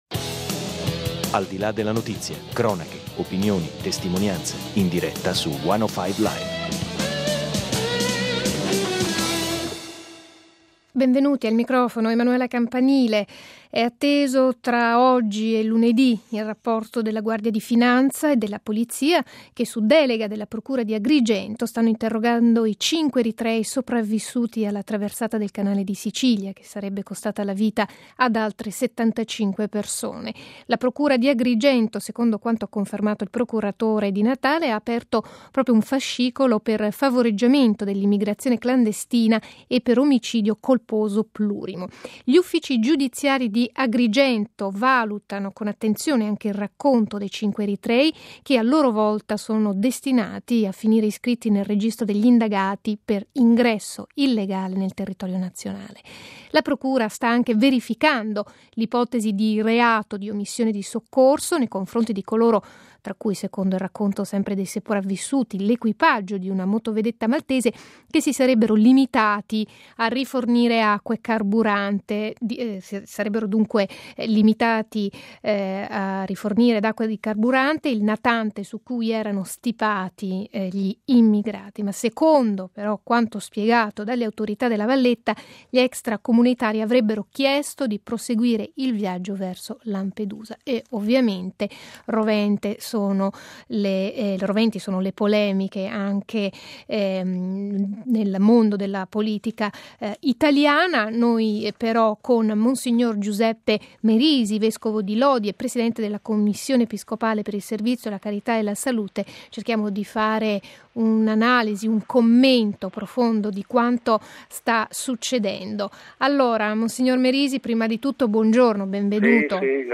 Al nostro microfono mons. Giuseppe Merisi , Presidente della Commissione Episcopale per il servizio della carità e la salute.